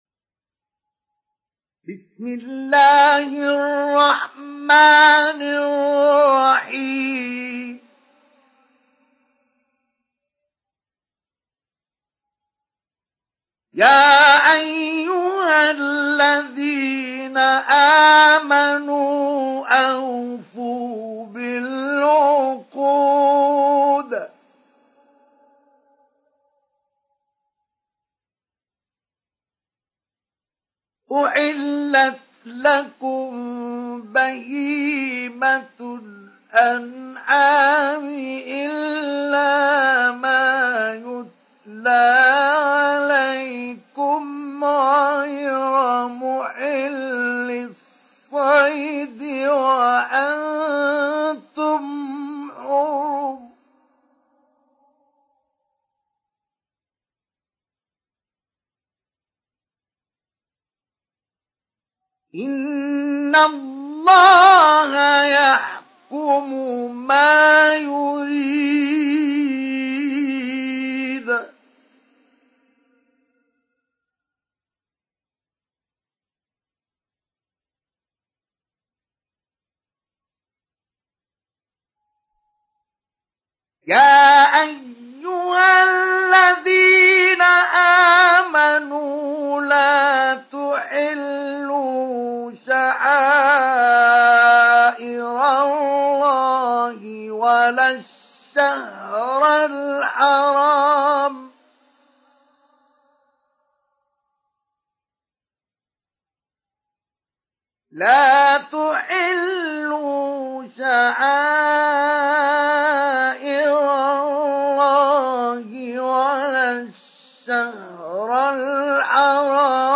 سُورَةُ المَائـِدَةِ بصوت الشيخ مصطفى اسماعيل